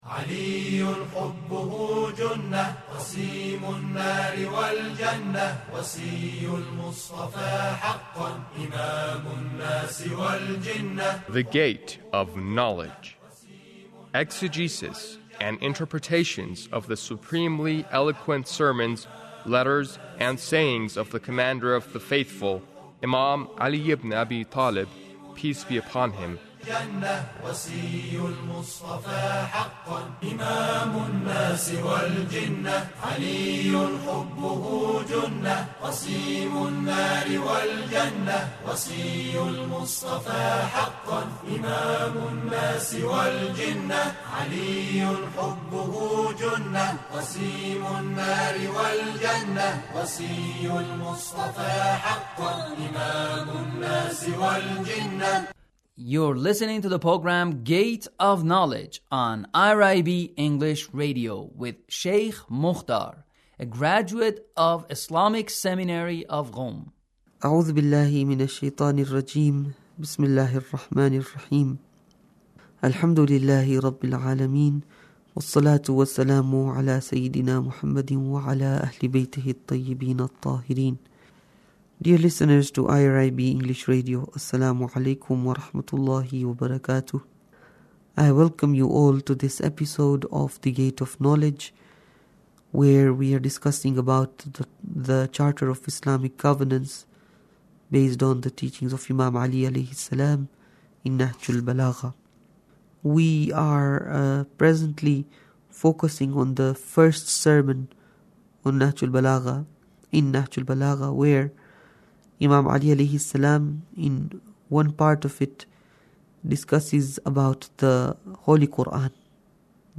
Sermon 1 -